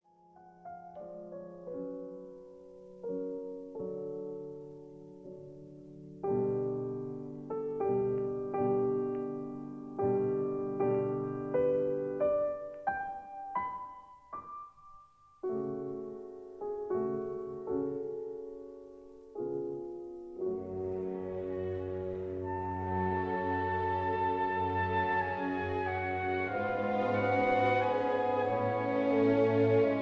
This is a 1960 stereo recording